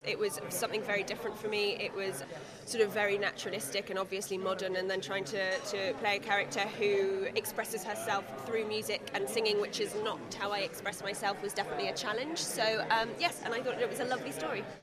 Keira Knightley told us it was tough singing in her latest film